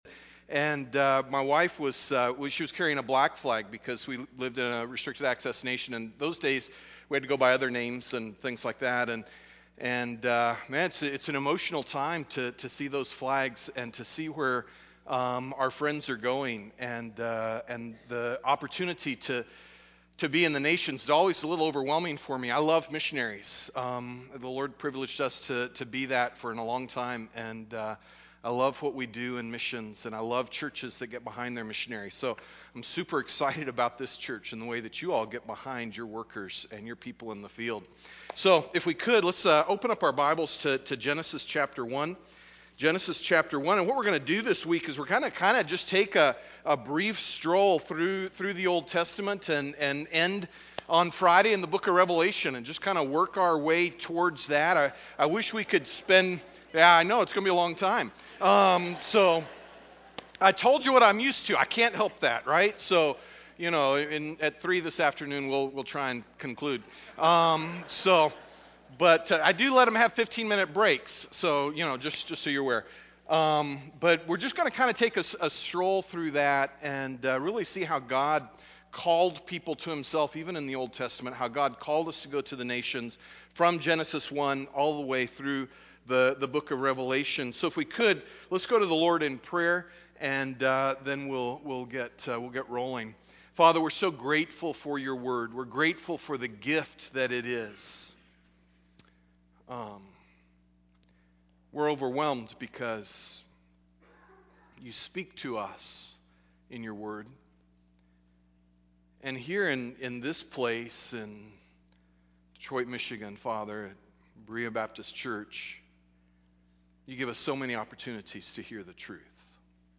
Global Missions Conference